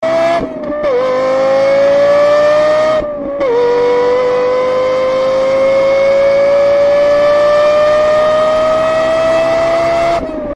Car REvvv
car-sound.mp3